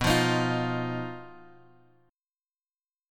B-Major Flat 5th-B-x,2,3,x,4,1.m4a